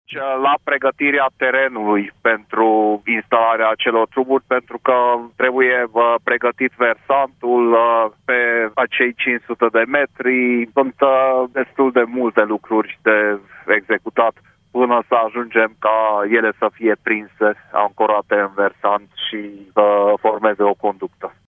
Între timp, se lucrează la amenajarea zonei unde vor fi instalate tuburile care au ca scop devierea pârâului Corund pentru a nu mai permite apei să se mai verse în mină, ne-a mai declarat prefectul: